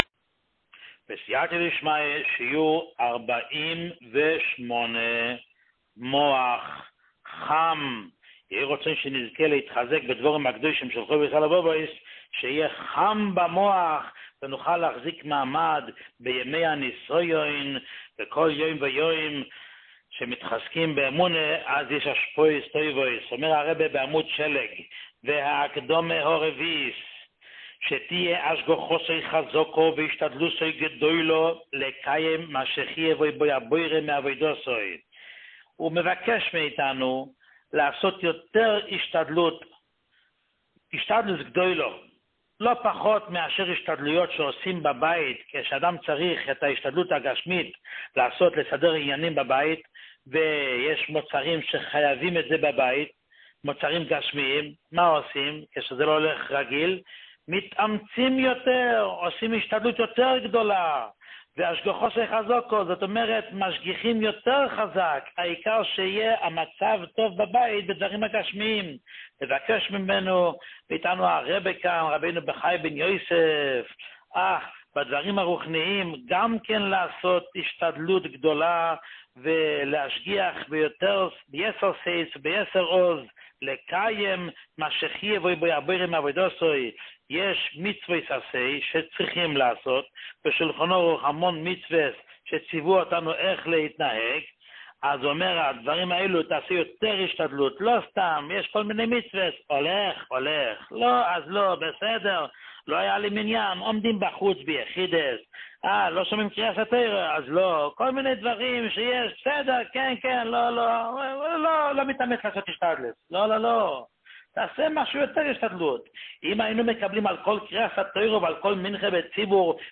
שיעורים מיוחדים
שיעור 48